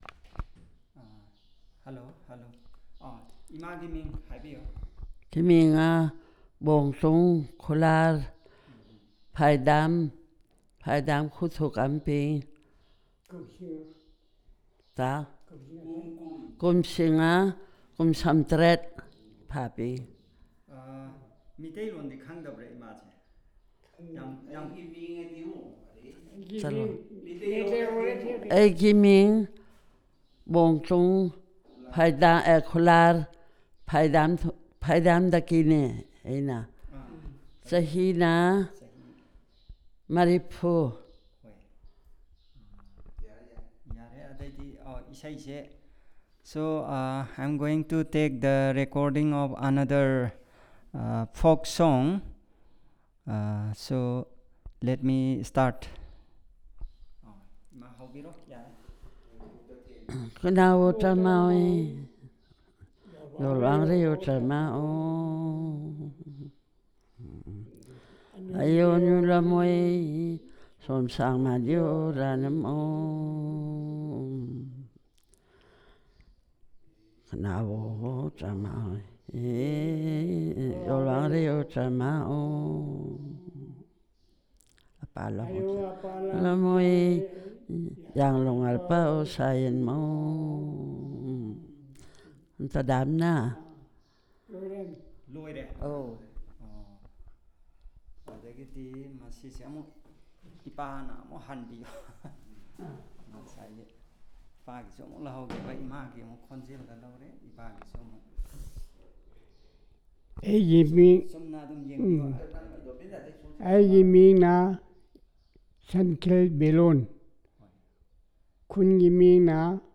Performance of the lullaby Mnau Mdo Pthlai Nki
singing a soft traditional song to console a child or to make a child go to sleep.